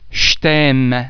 the [j] under the influence of the [t] is pronounced [sh]